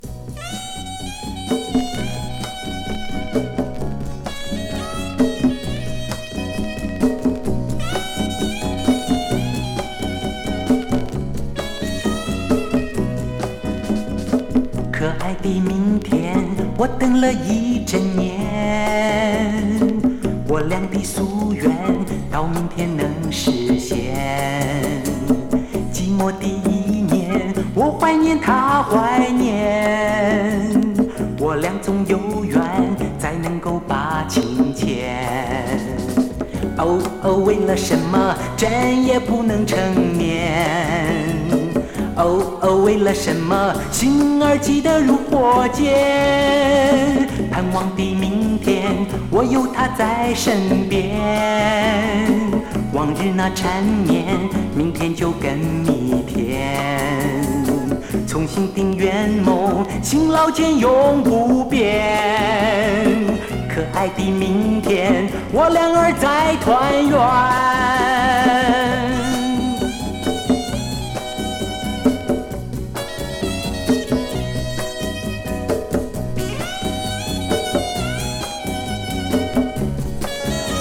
パーカッションと、やさしい歌声が最高にマッチした
陽気なシンセポップ